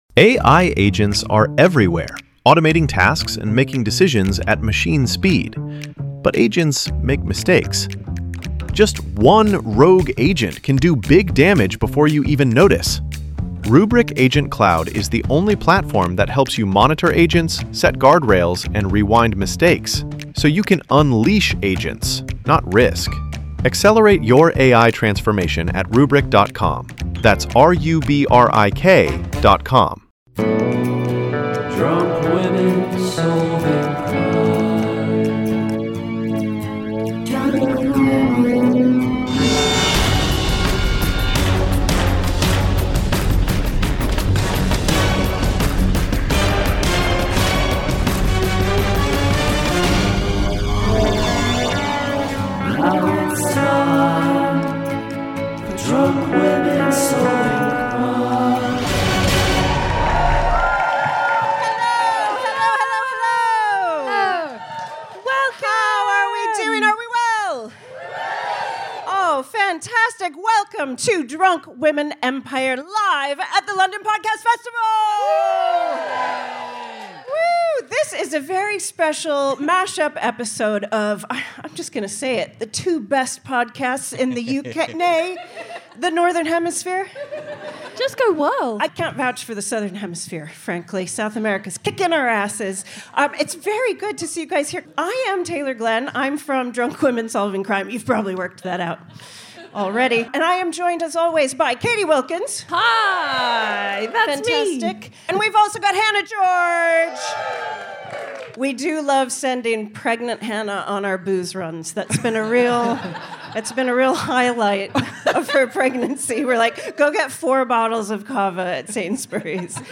Drunk Women Empire (Live At The London Podcast Festival 2023)
Anyway, we had a simply lovely time with the Drunk Women gang, solving a true-life Hollywood crime, suggesting our own entries for Worst Movie Crime, and then helping to crack the case of an audience member or two. It was raucous, hilarious, and features the best Jennifer Coolidge impression this side of... well, of Jennifer Coolidge.